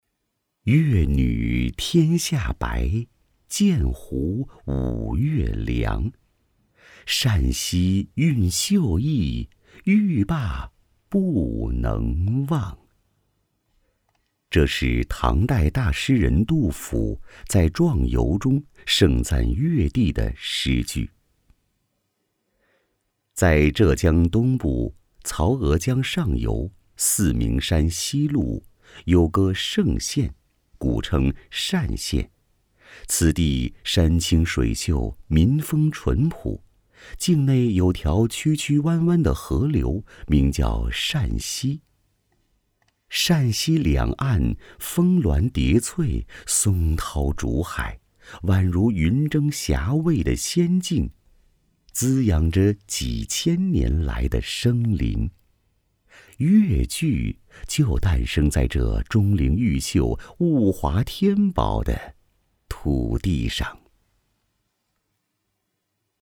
男52